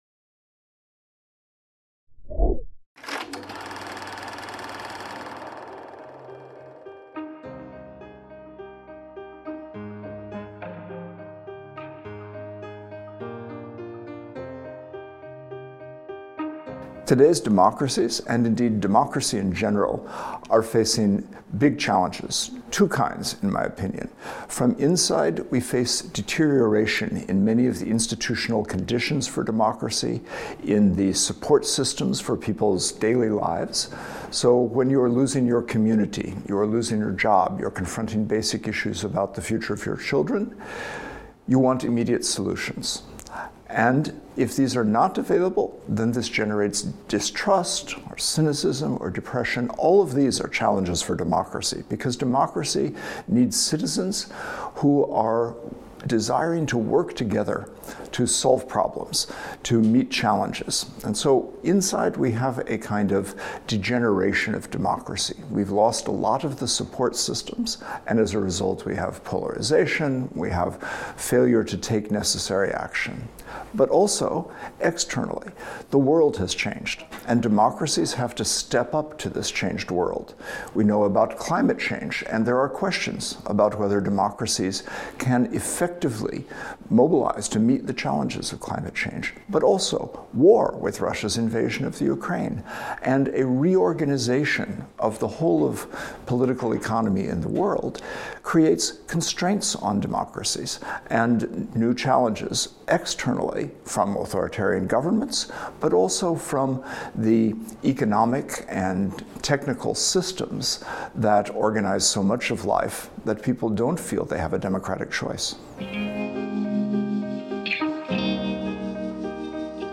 Craig Calhoun's interview | Canal U
Craig Calhoun's interview about the fragility of democracy